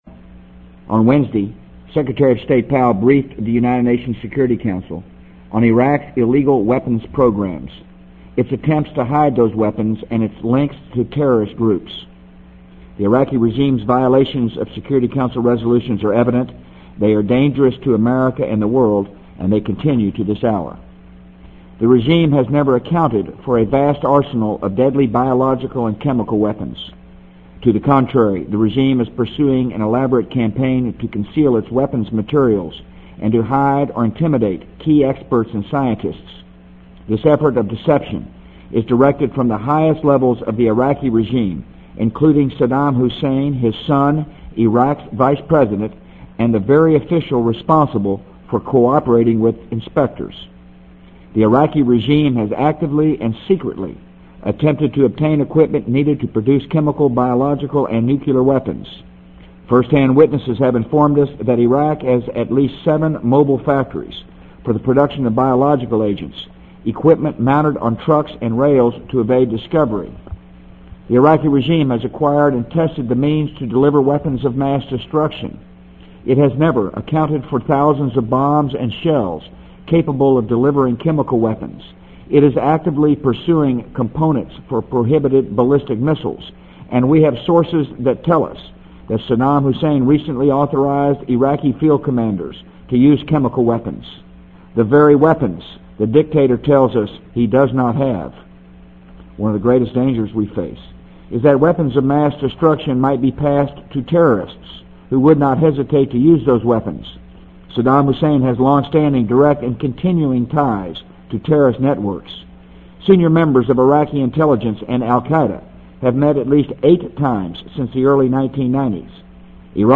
【美国总统George W. Bush电台演讲】2003-02-08 听力文件下载—在线英语听力室